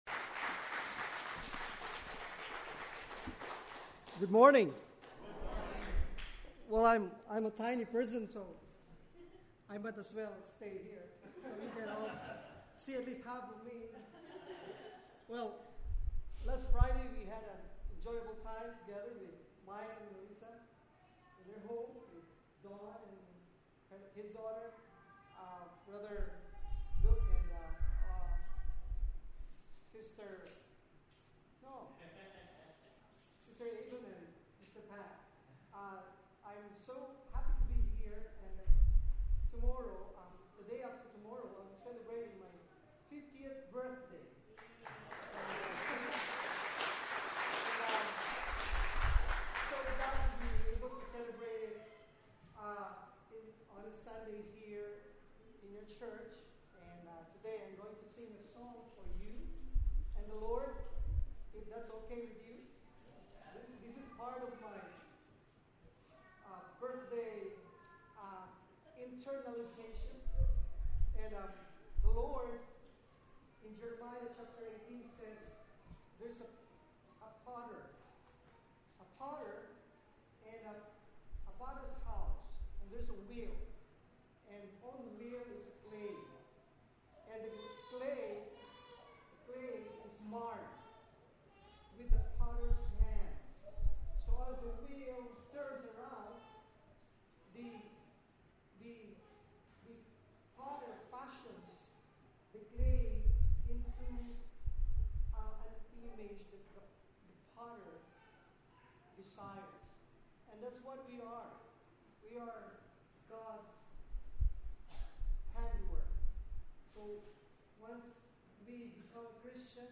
Listen to the full Sermon here: